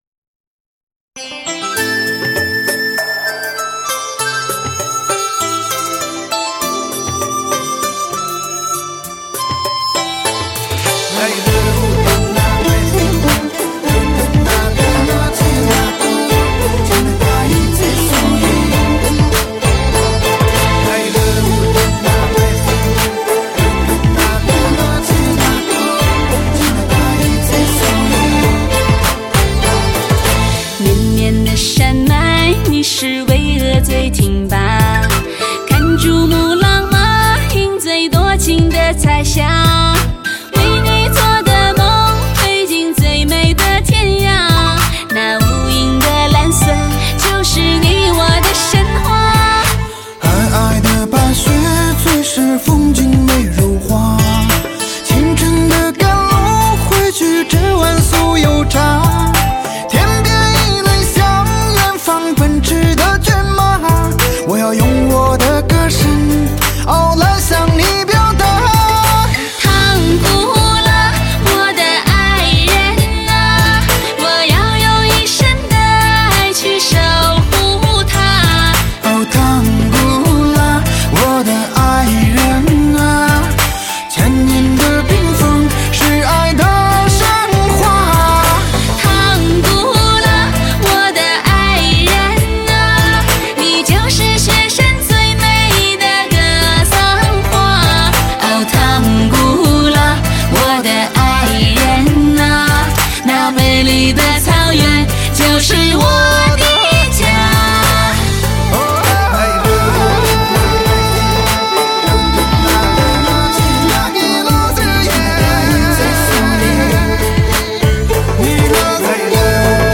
聆听极品人声 绝美非凡的韵色
脱俗非凡的人声天籁 HI-FI人声高解析 高临场天碟